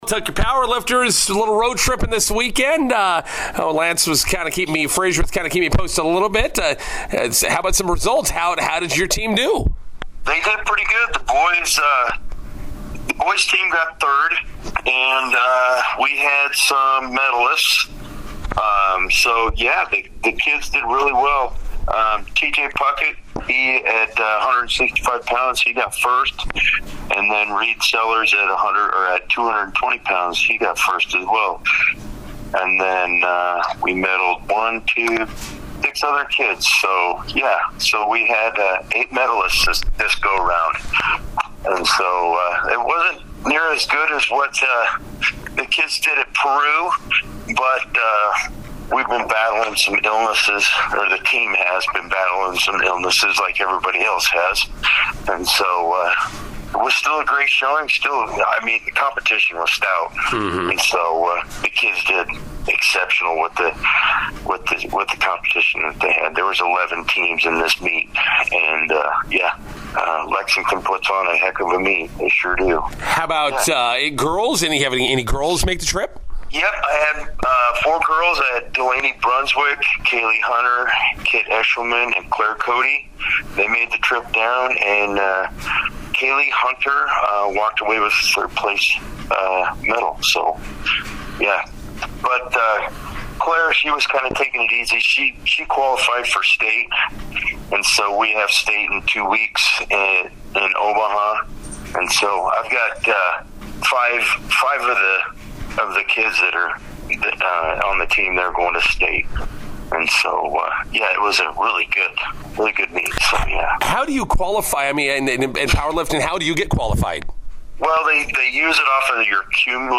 INTERVIEW: Bison Powerlifters finish third at Lexington.